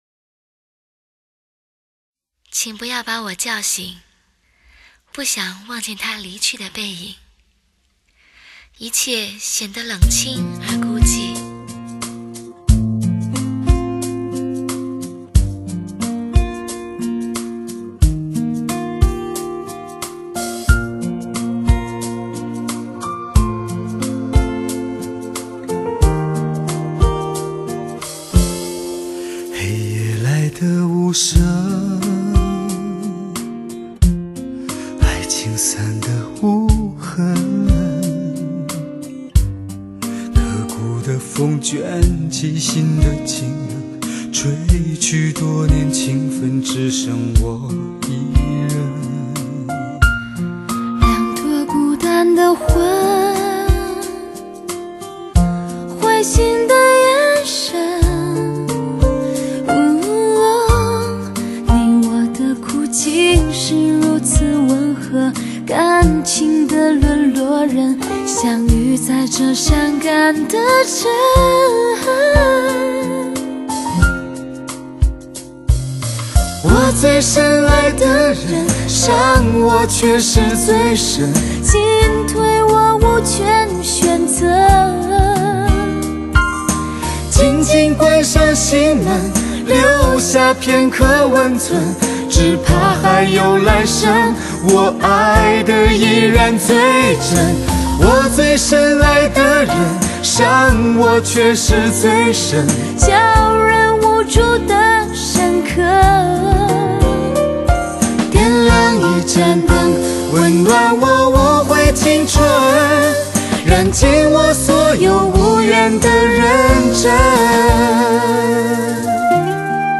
感性极致，人声翘楚。
真正实现高清，全方位360环绕3D立体音效。信噪比高达120dB以上的专业品质，保护爱车音响，支持正版唱片。